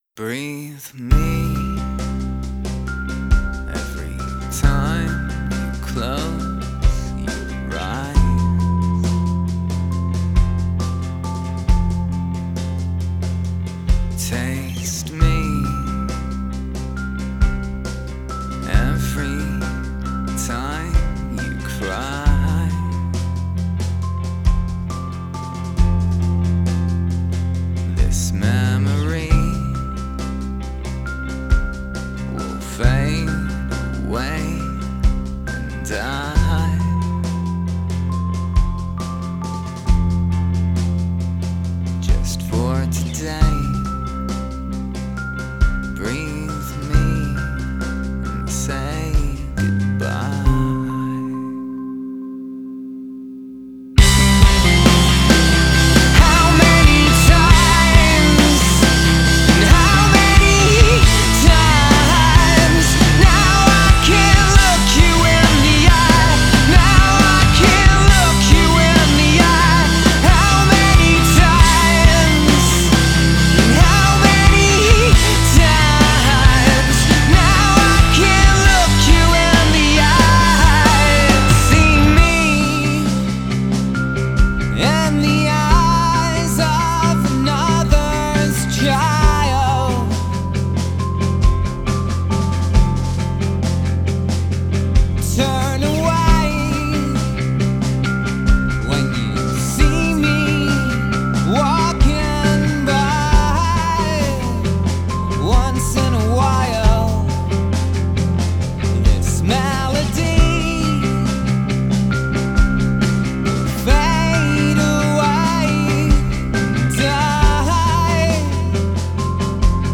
Alternative rock Indie rock Post punk